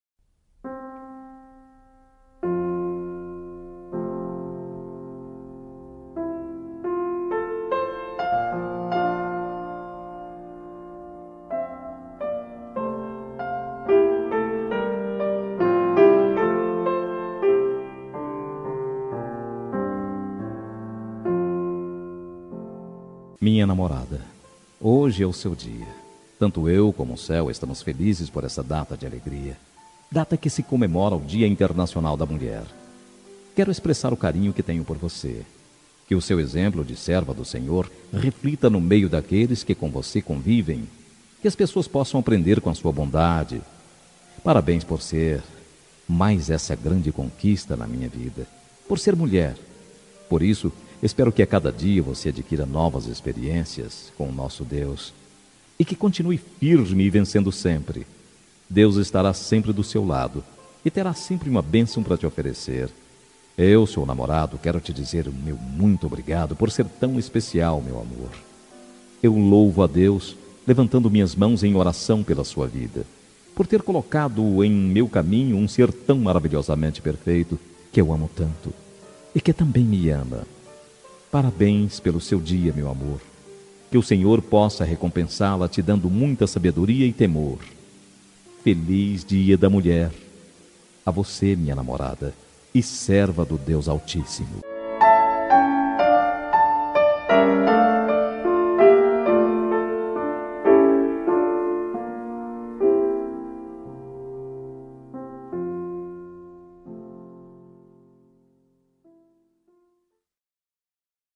Dia das Mulheres Para Namorada – Voz Masculina – Cód: 53080 – Evangélica